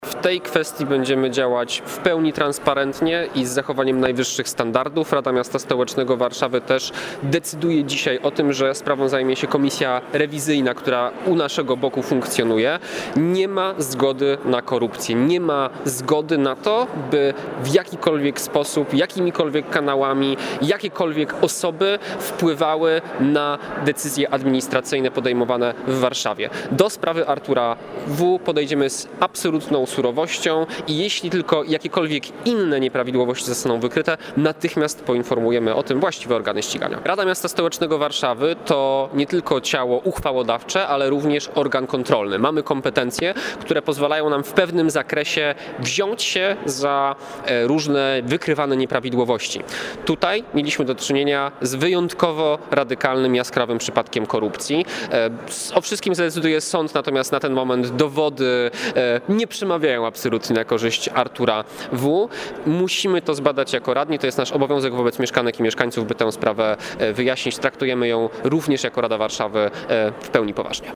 Radny Marek Szolc: